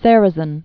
(sârə-zən), Gene 1902-1999.